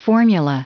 Prononciation du mot formula en anglais (fichier audio)
Prononciation du mot : formula